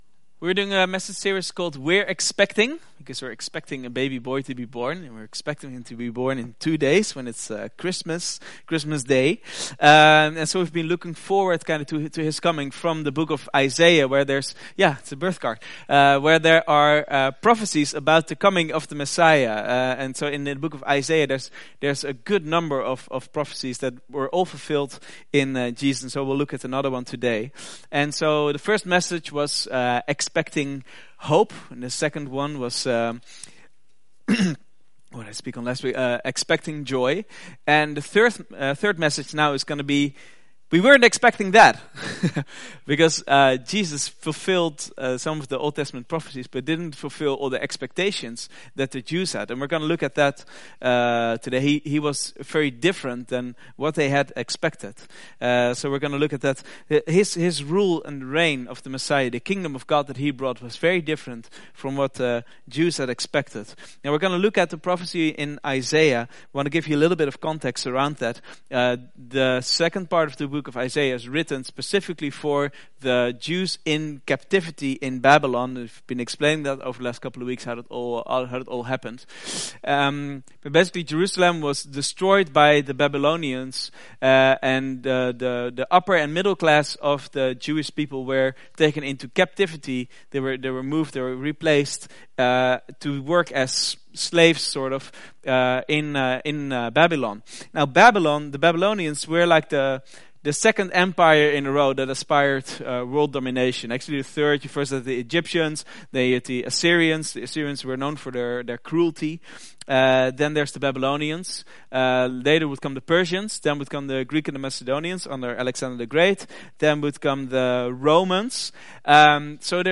Vineyard Groningen Sermons We didn't expect that!